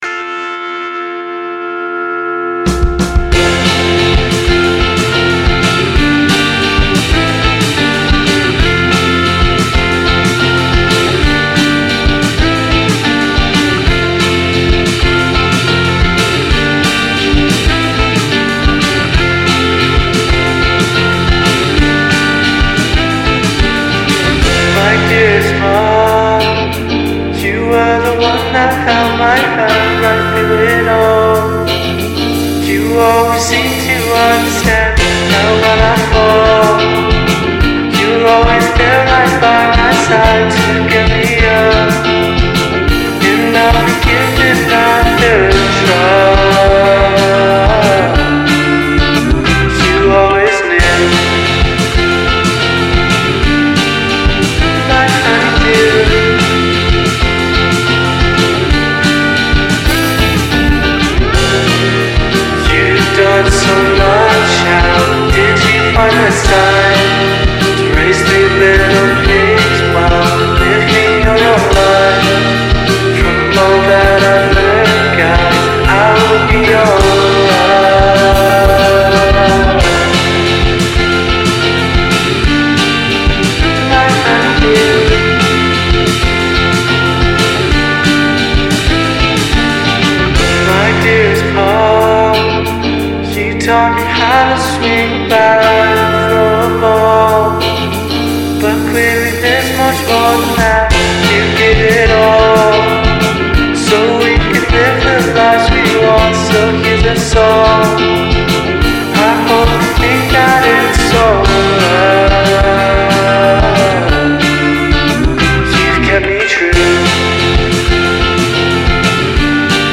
infectious lo-fi sound